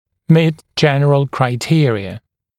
[miːt ‘ʤenrəl kraɪ’tɪərɪə][ми:т ‘джэнрэл край’тиэриэ]соответствовать общим критериям